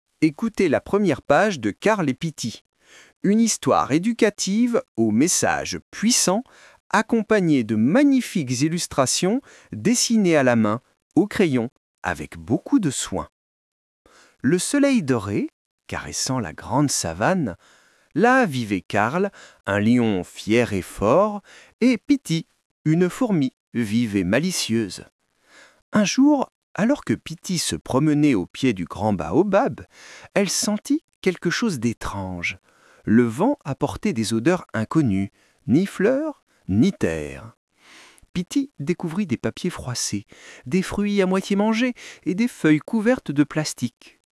Lectures (audio)